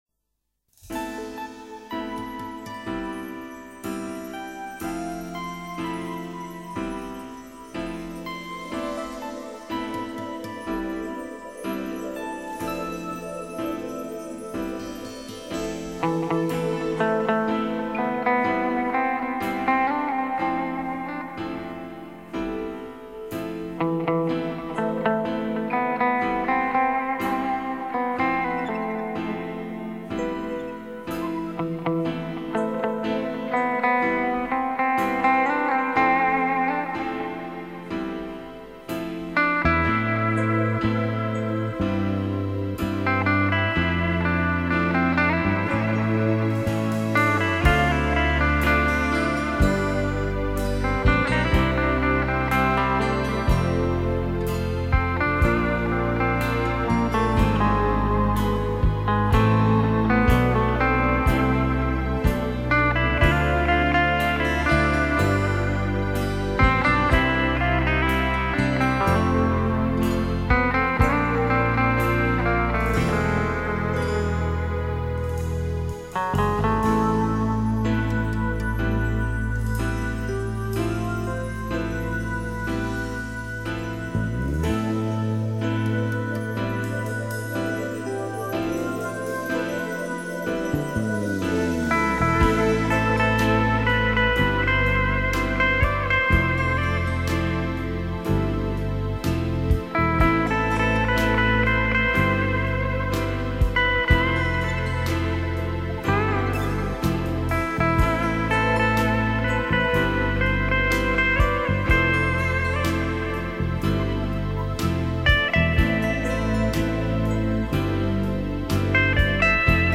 流派：Rock